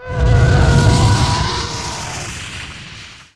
roar4.wav